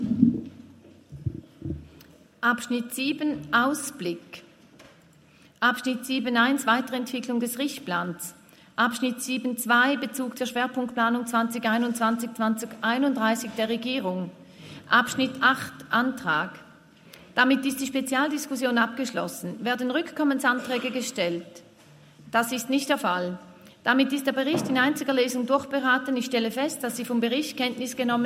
Schöb-Thal, Ratspräsidentin, stellt Kenntnisnahme der Berichterstattung 2022 über den kantonalen Richtplan fest.
Session des Kantonsrates vom 18. bis 20. September 2023, Herbstsession